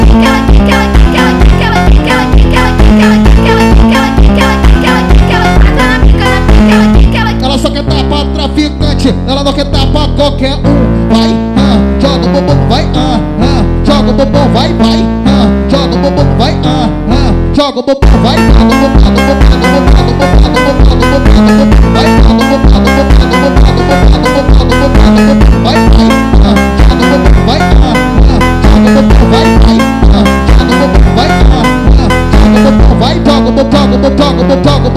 Модульные синтезаторы и глитчи трека
Electronic Electronica
Жанр: Электроника